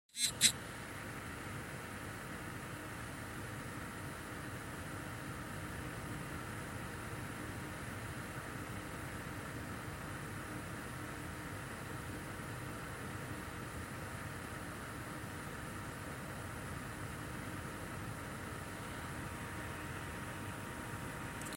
Запись с диктофона, расположенного в паре сантиметров от крыльчаток Manli GeForce GTX 1060 Gallardo, при 50%.